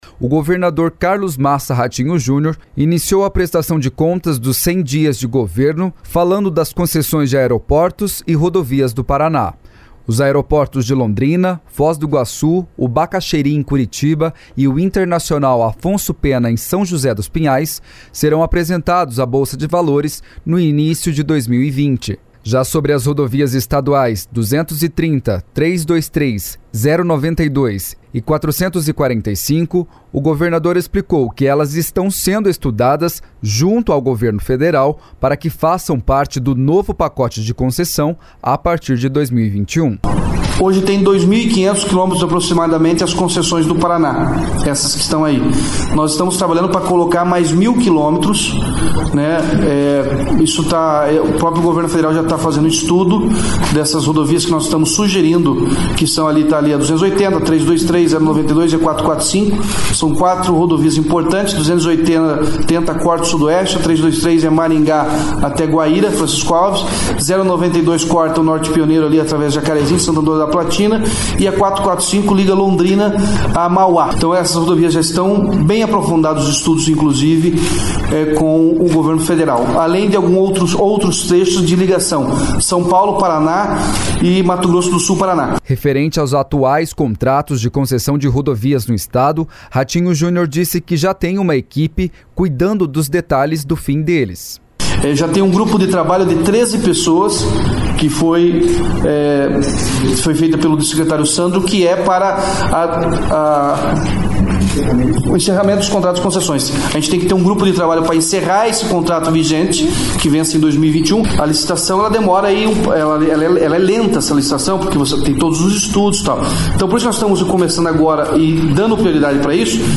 A afirmação foi feita na audiência convocada para prestar contas dos primeiros 100 dias de governo. Ratinho Júnior ainda falou sobre estradas, pedágios e economia na máquina pública.
Outro novo projeto do governo é o de iluminar algumas rodovias no estado, como explicou o governador Ratinho Junior.